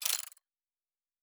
pgs/Assets/Audio/Fantasy Interface Sounds/UI Tight 27.wav
UI Tight 27.wav